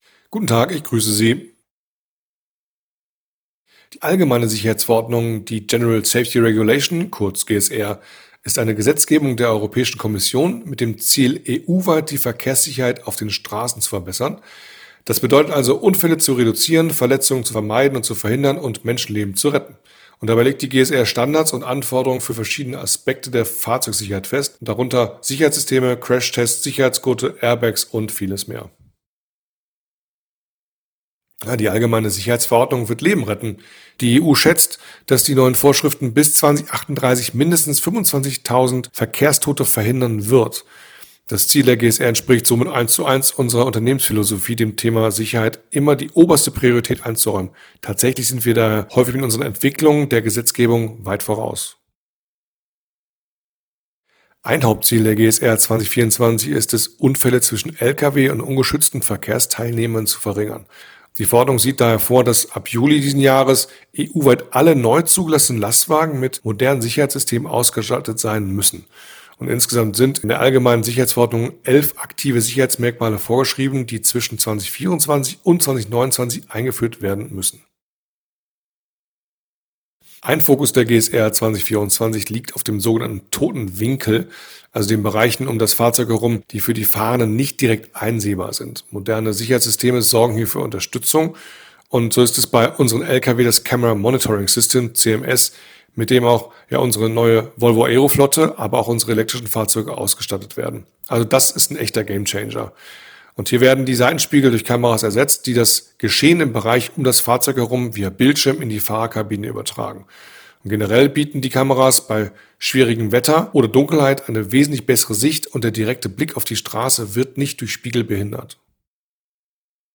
Interview: Gamechanger! LKW-Sicherheit im Straßenverkehr.